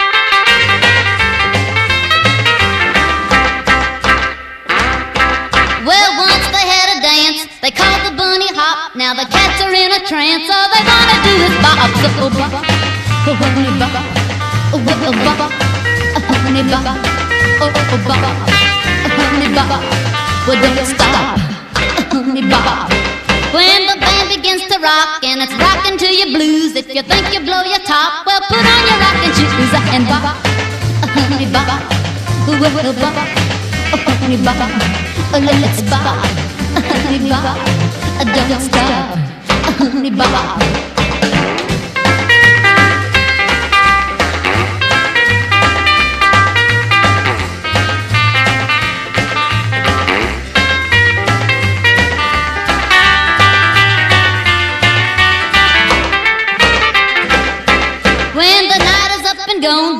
EASY LISTENING / VOCAL / 60'S / NOVELTY
グルーヴィーな